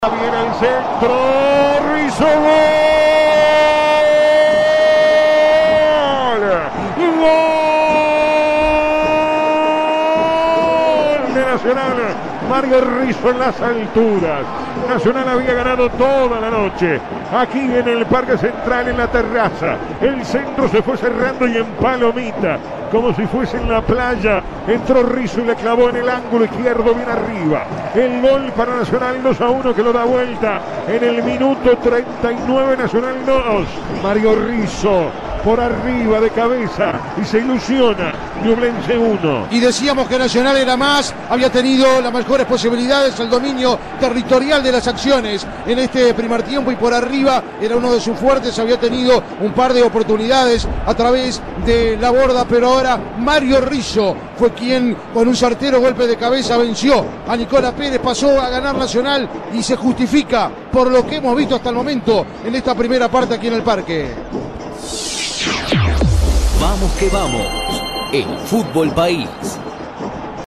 Relato Vamos que vamos: